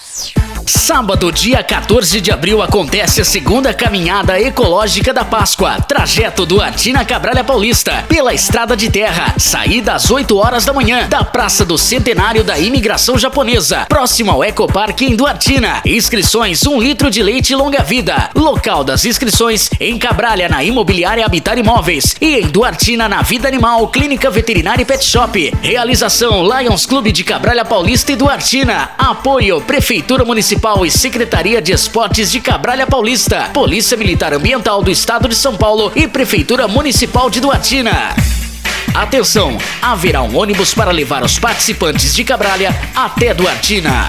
CLIQUE AQUI E OU�A O SPOT PRODUZIDO PARA A CAMINHADA DE P�SCOA DO LIONS CLUBE DE CABR�LIA PAULISTA